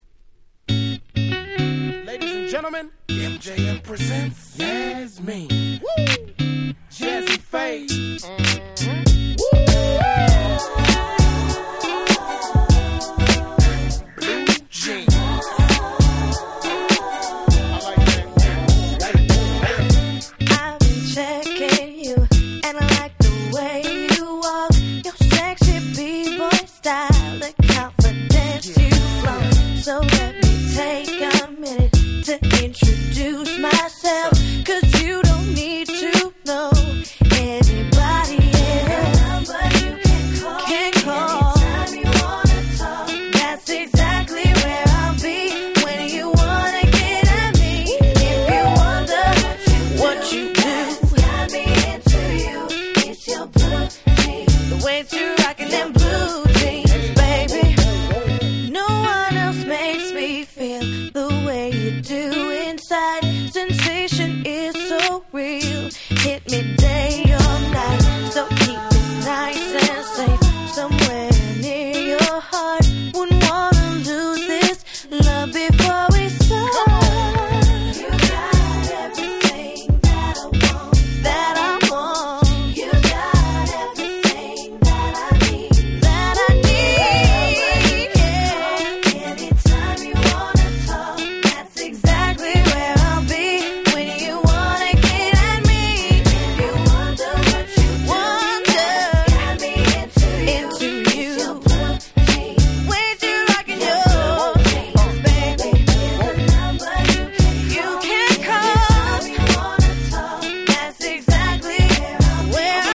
02' Nice R&B / Neo Soul !!
カッティングギターの音色が心地良い若さ溢れるめっちゃ可愛い良曲！！
ネオソウル